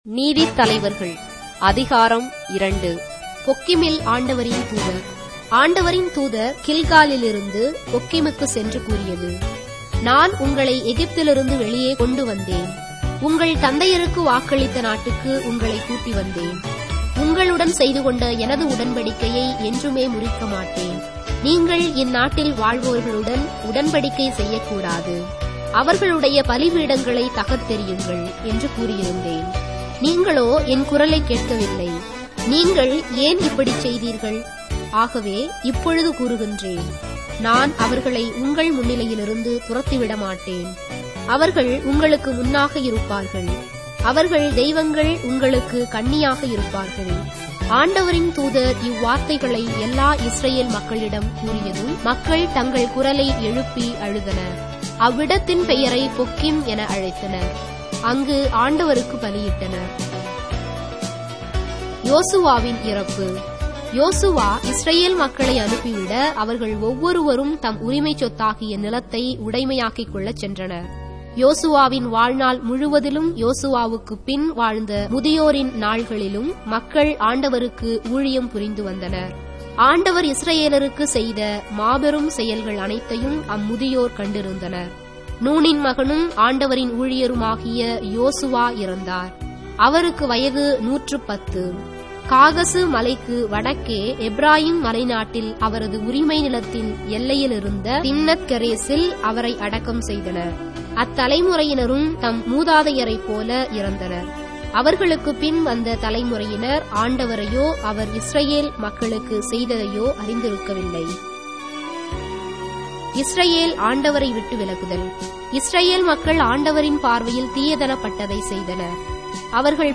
Tamil Audio Bible - Judges 9 in Ecta bible version